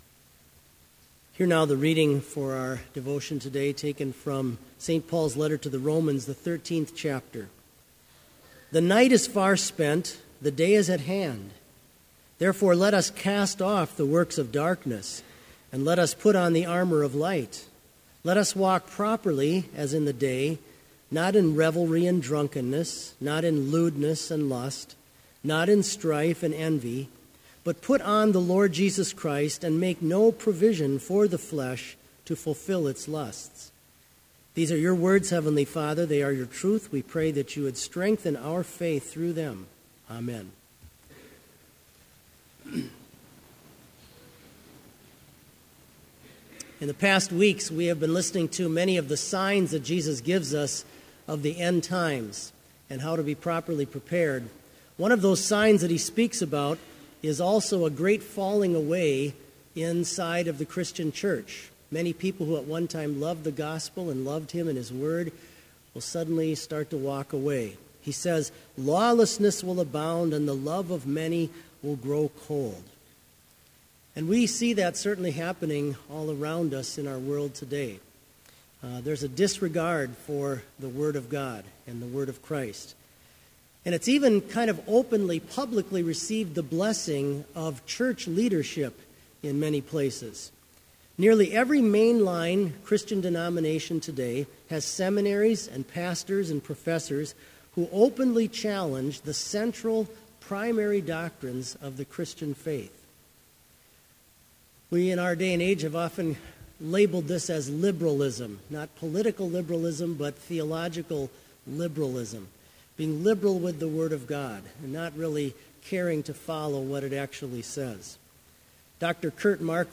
Sermon Only
This Chapel Service was held in Trinity Chapel at Bethany Lutheran College on Tuesday, December 1, 2015, at 10 a.m. Page and hymn numbers are from the Evangelical Lutheran Hymnary.